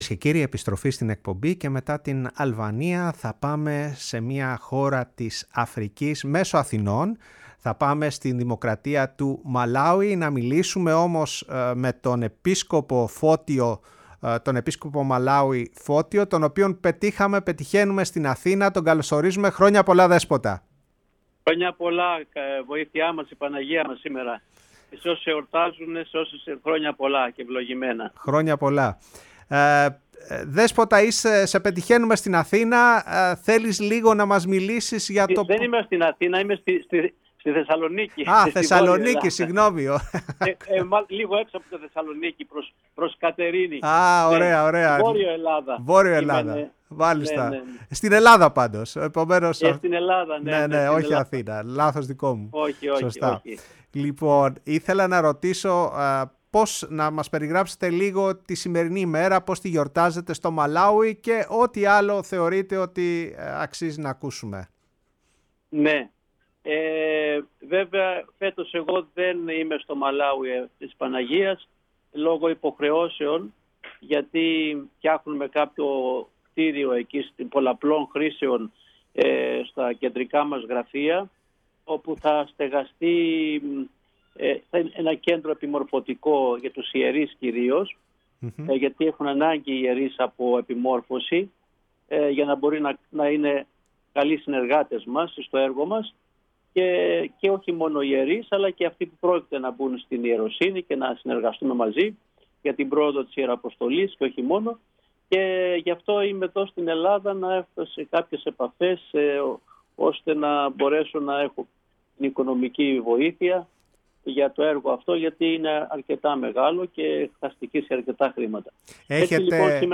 μιλώντας στο ραδιόφωνο της Φωνής της Ελλάδας